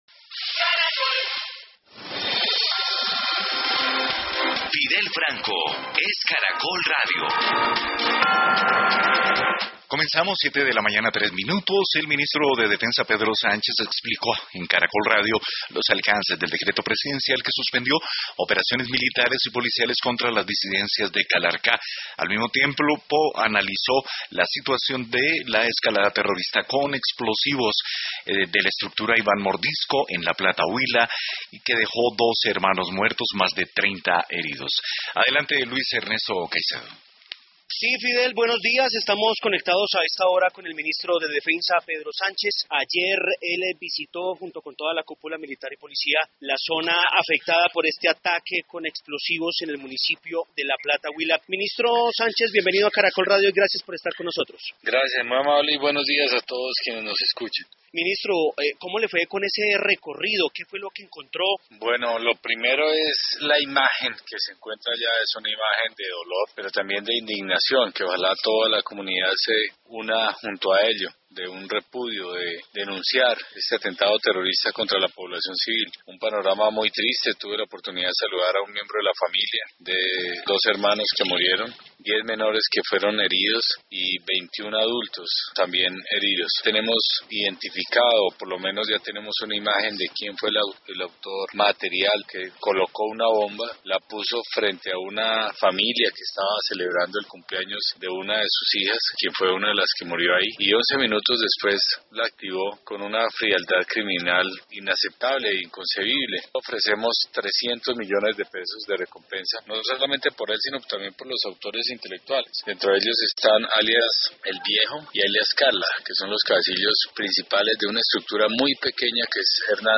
El ministro de Defensa, Pedro Sánchez, explicó en el noticiero de la mañana de Caracol Radio los alcances del decreto presidencial que suspende las operaciones militares y policiales contra las disidencias de alias ’Calarcá’ por un mes.